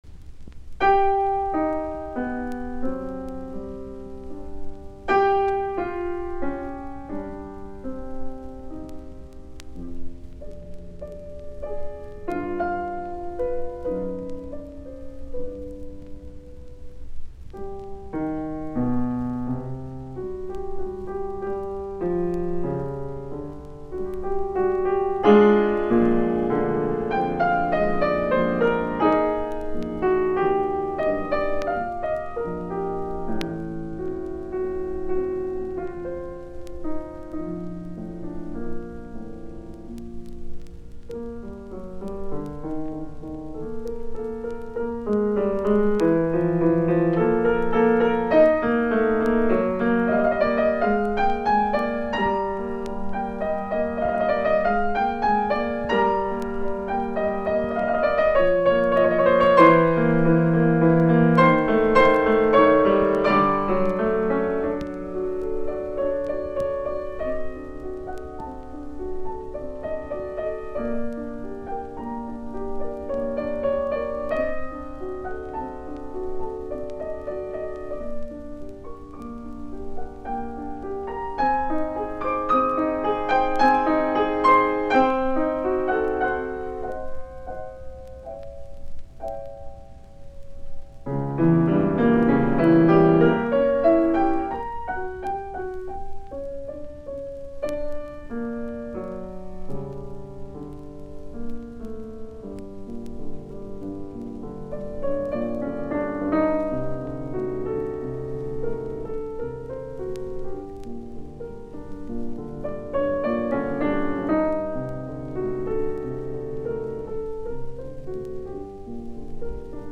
musiikkiäänite
Soitinnus: Piano.